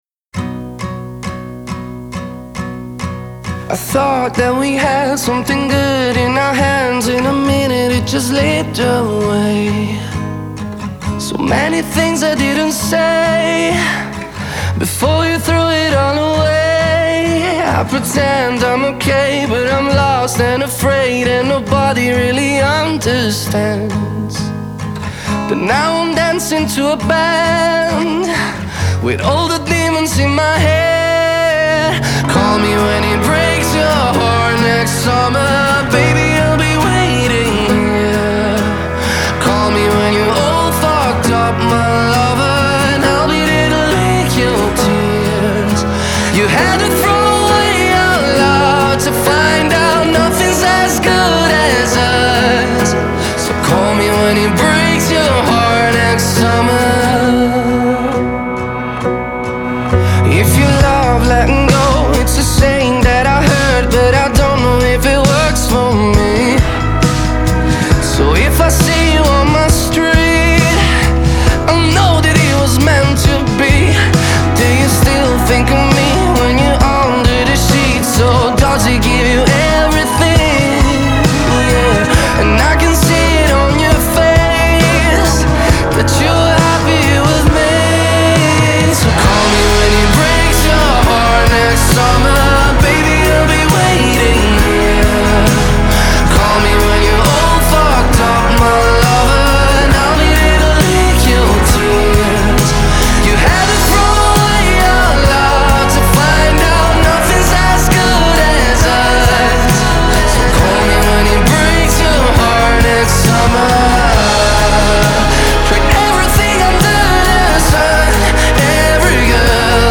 Pop, Dance